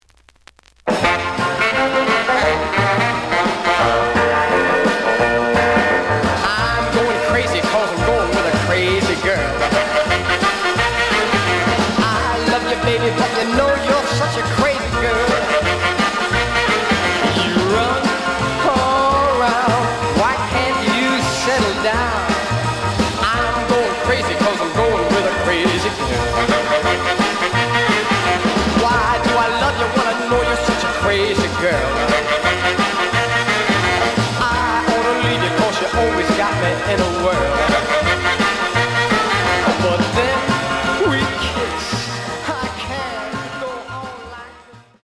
The audio sample is recorded from the actual item.
●Genre: Rhythm And Blues / Rock 'n' Roll
Some damage on both side labels. Plays good.)